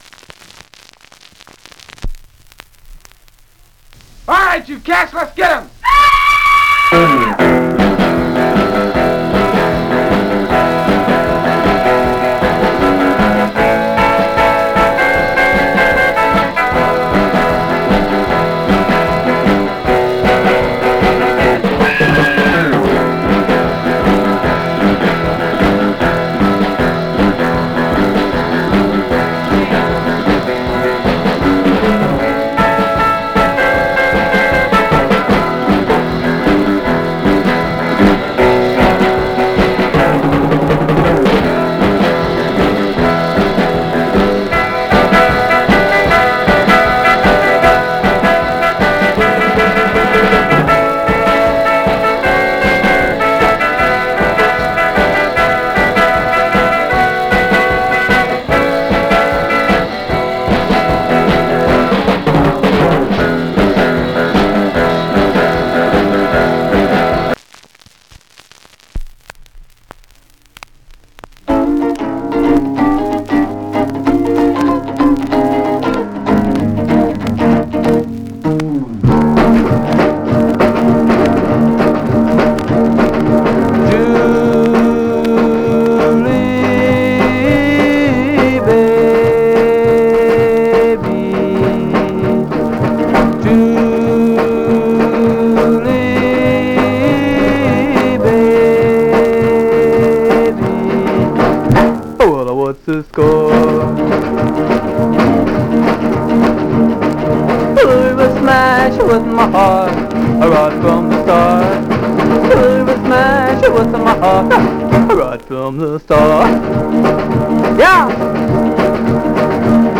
Surface noise/wear Stereo/mono Mono
Rockabilly